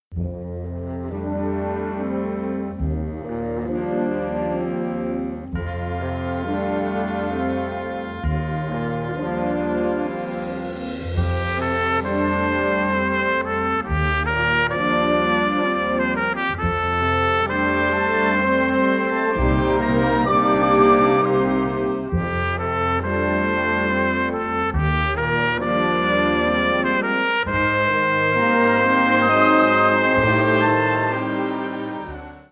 Subcategorie Concertmuziek
Bezetting Ha (harmonieorkest)